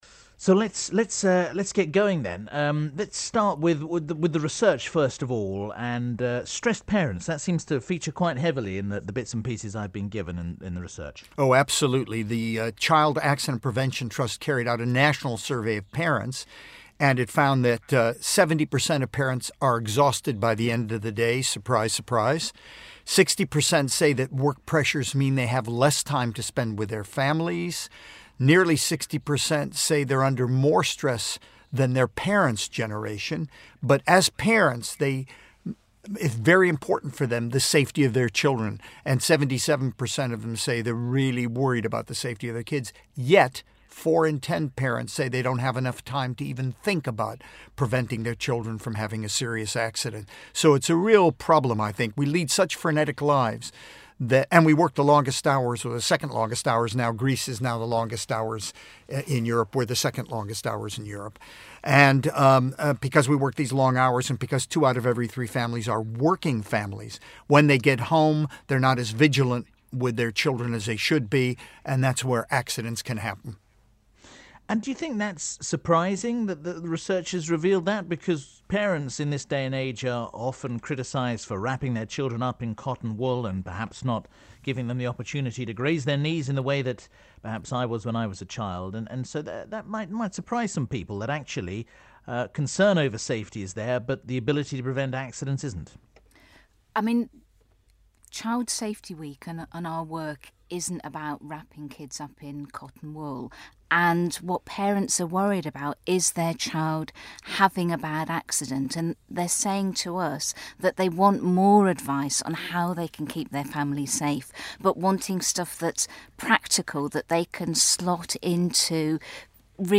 Sir Cary Cooper talks about child safety on BBC Southern Counties